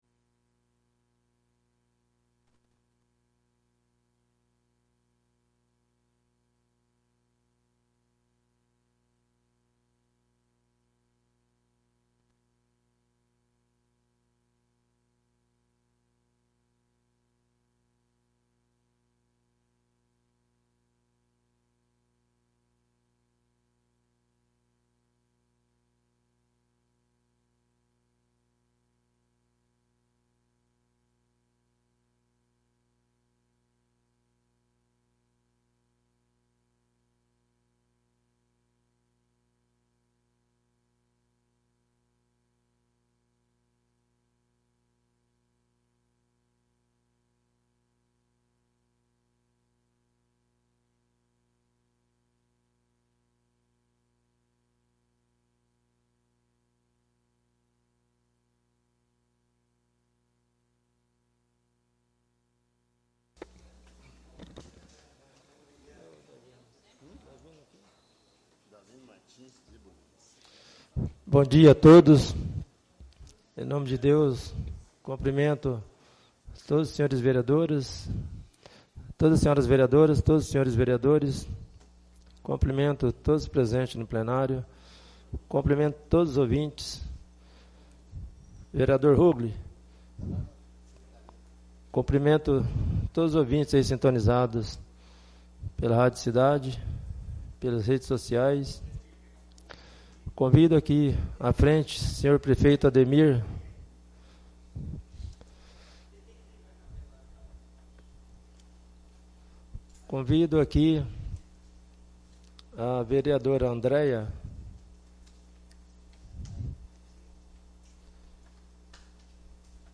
1° SESSÃO SOLENE DA POSSE DO 1° SUPLENTE DAVINO MARTINS DE BULHÕES